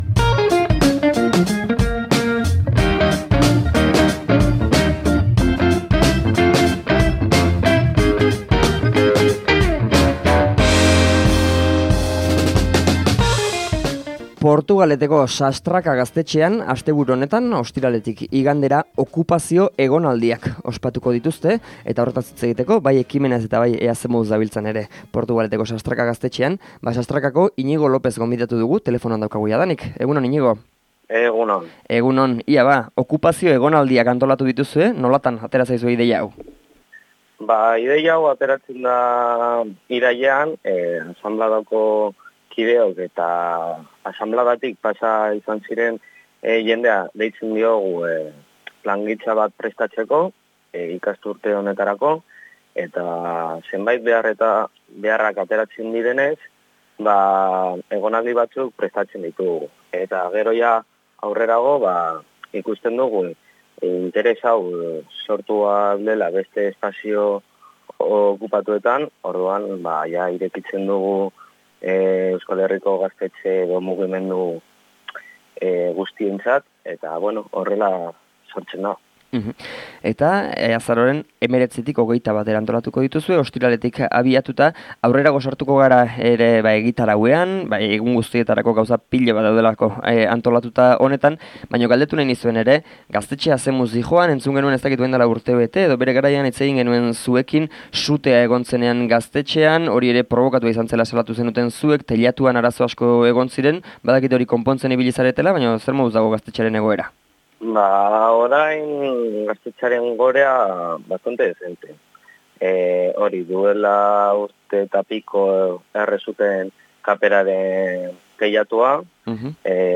Elkarrizketa osoa hemen entzungai.